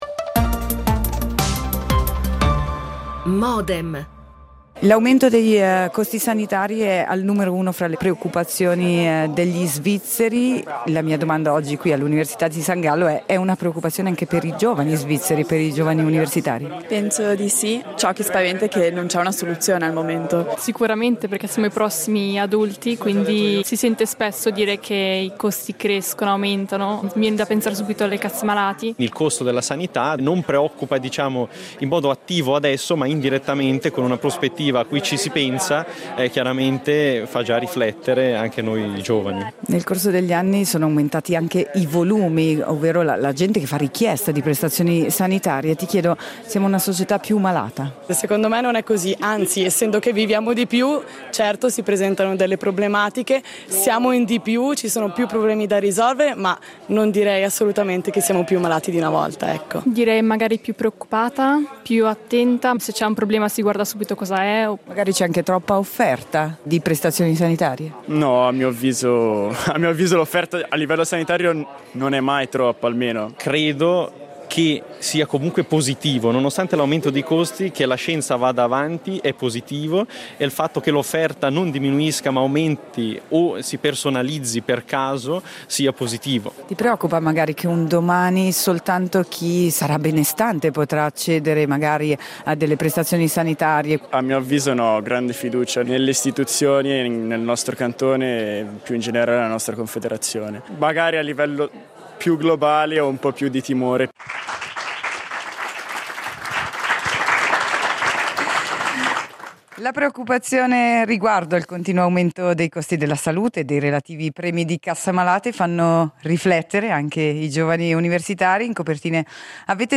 Una puntata di Modem evento, in occasione del Ticino Day all’Uni di San Gallo
Per capire dove sta andando la sanità del nostro Paese, Modem è in trasferta, per un dibattito su questo tema in occasione del Ticino Day organizzato ogni anno dagli studenti ticinesi dell’Università di San Gallo.